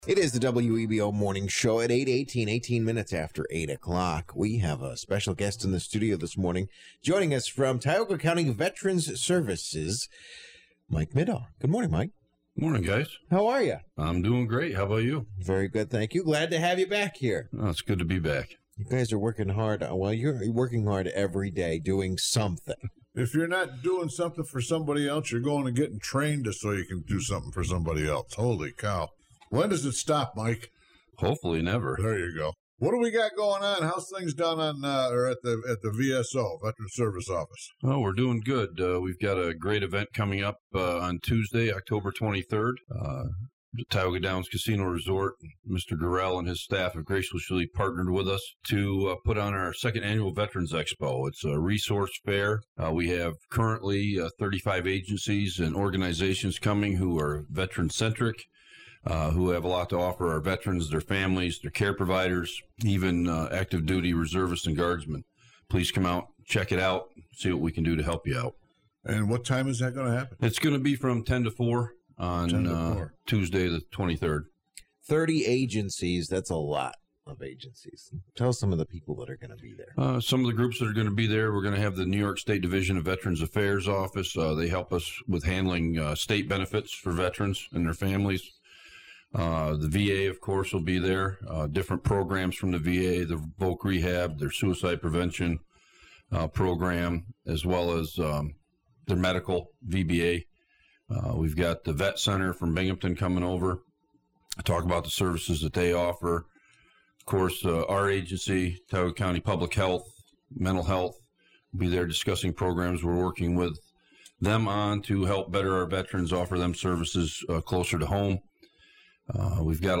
For details about this event, listen to the morning show interview below: